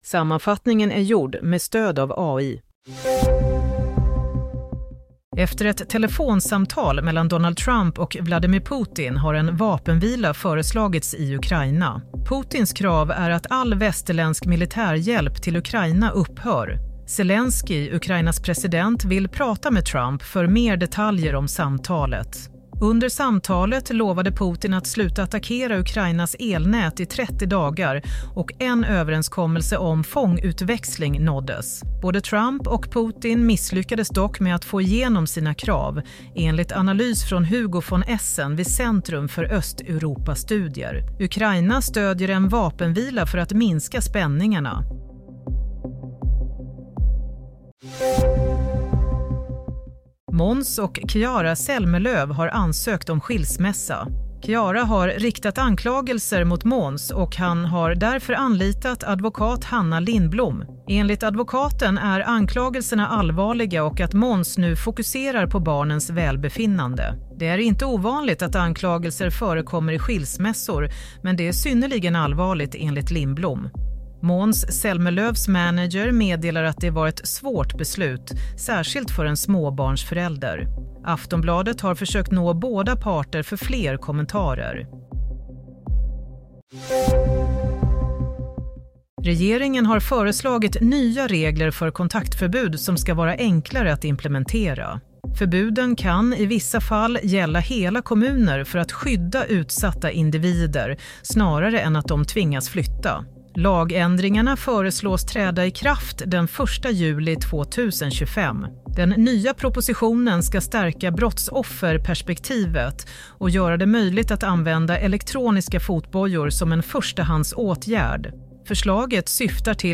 Nyhetssammanfattning - 18 mars 22:00